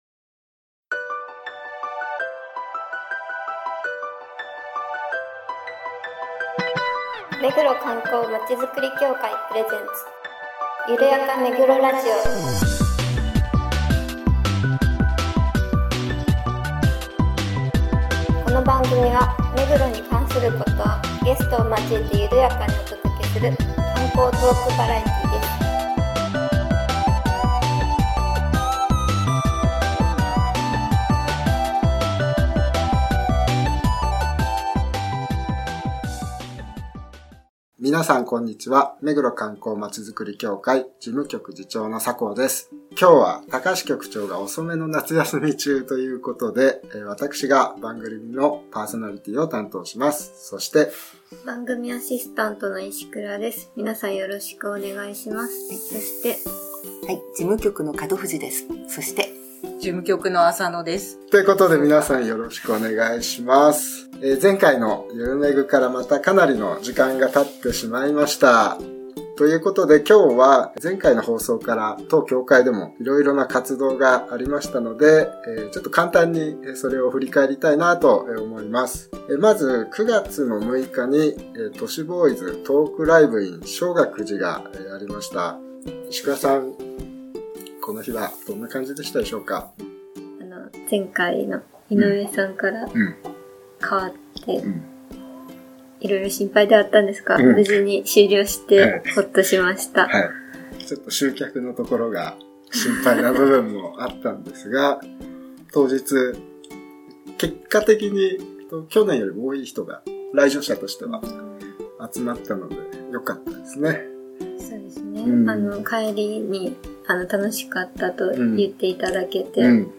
観光協会の面々が目黒に関する観光情報などゲストを交えて会話を展開するゆるやかトークバラエティ番組です。